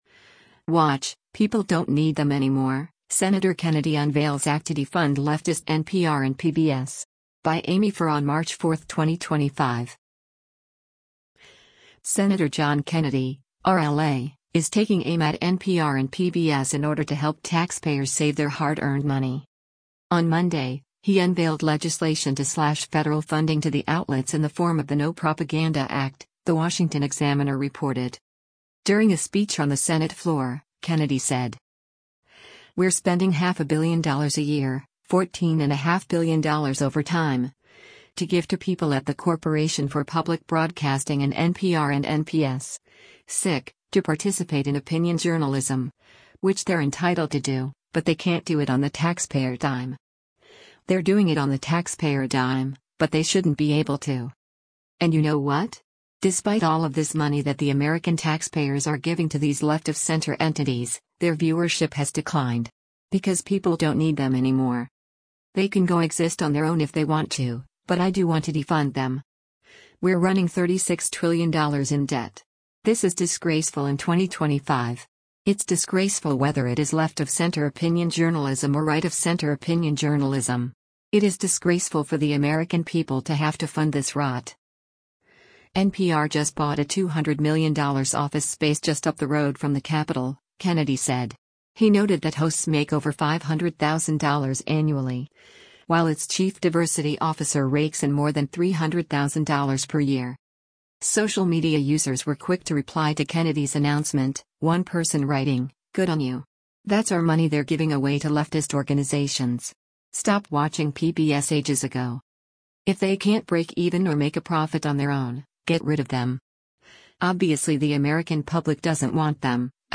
During a speech on the Senate floor, Kennedy said: